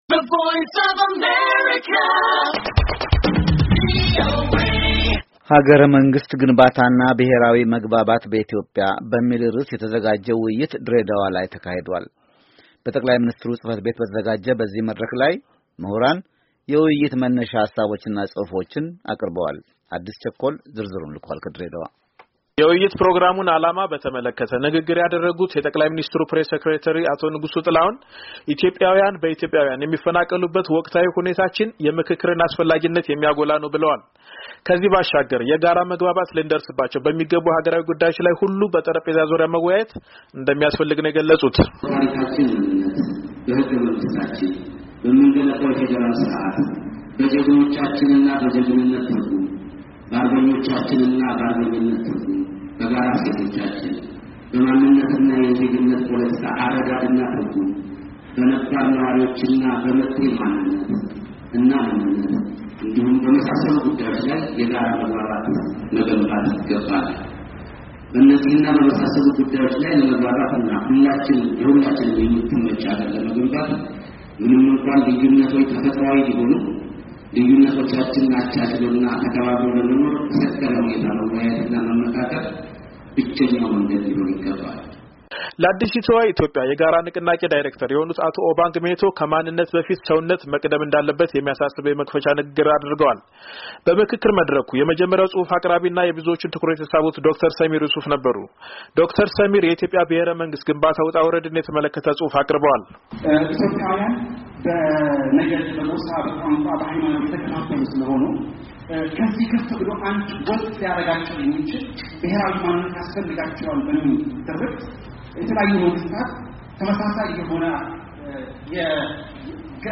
ዜና
“ሐገረ-መንግሥት ግንባታና ብሄራዊ መግባባት በኢትዮጵያ” በሚል ርዕስ የተዘጋጀ ውይይት ድሬ ዳዋ ላይ ተካሂዷል።